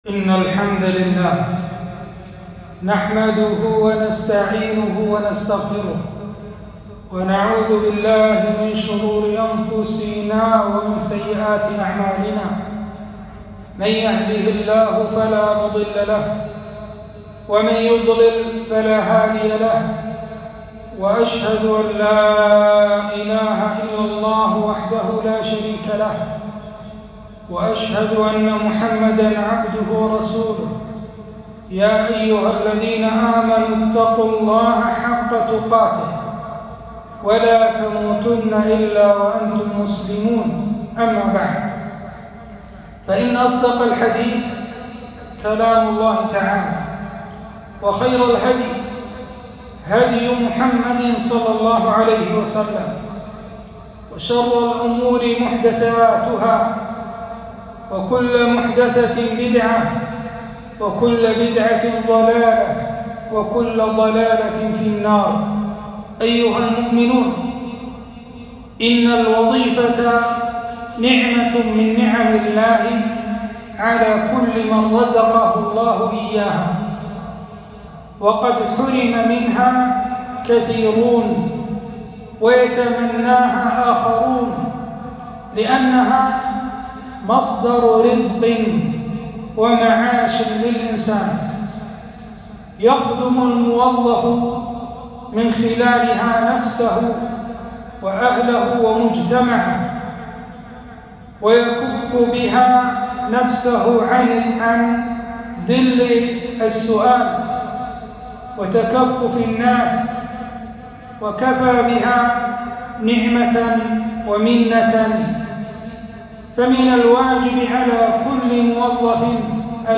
خطبة - أخلاقيات الموظف في شريعة الإسلام